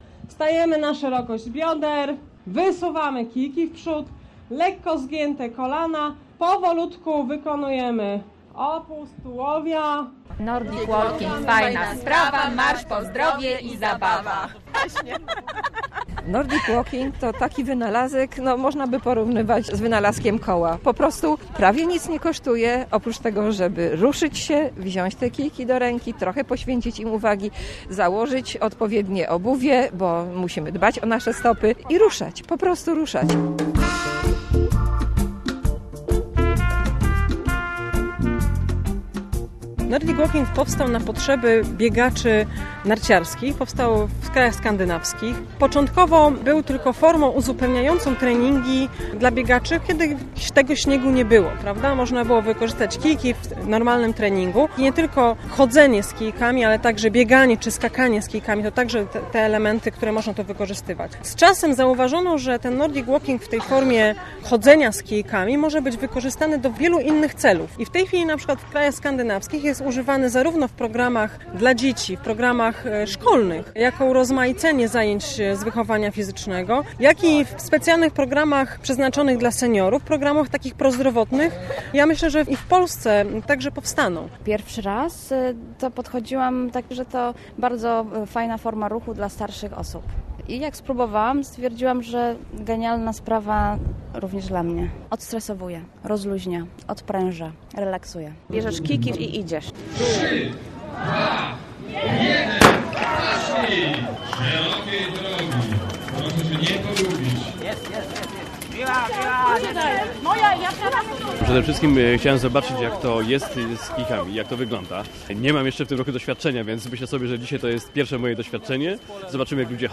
Cuda na kiju - reportaż